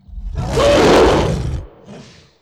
c_goril_atk3.wav